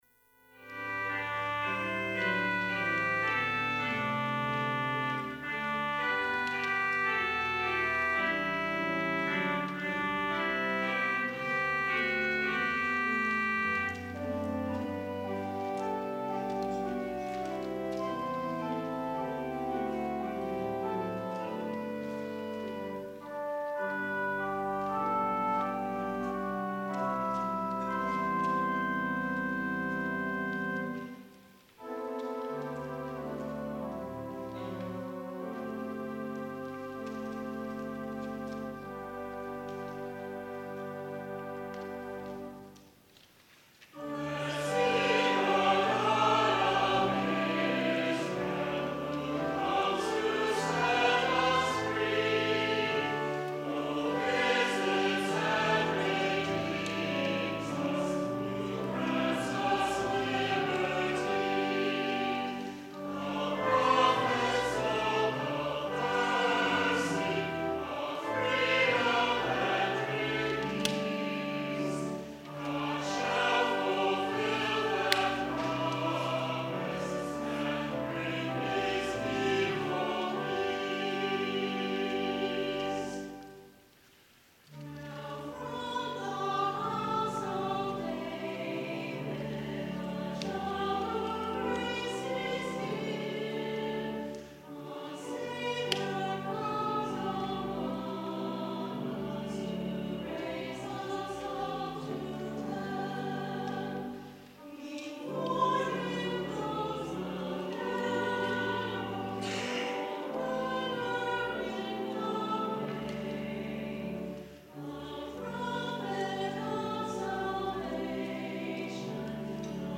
Chancel Choir
organ